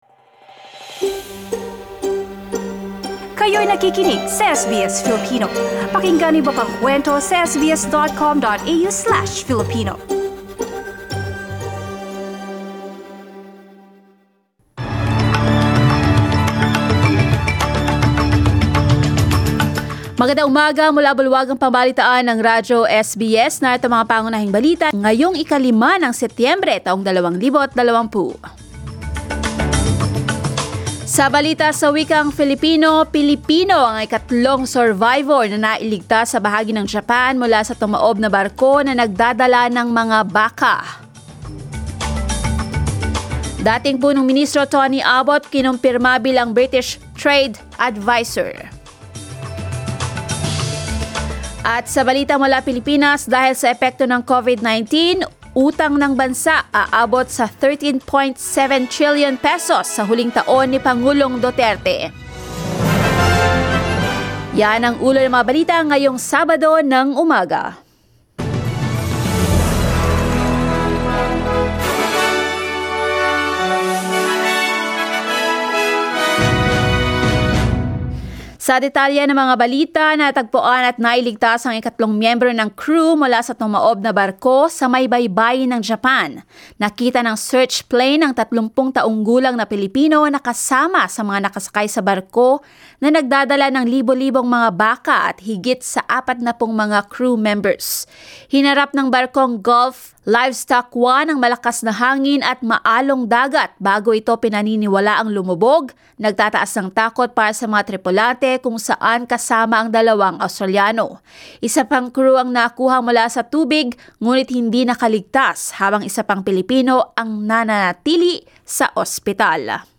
SBS News in Filipino, Saturday 05 September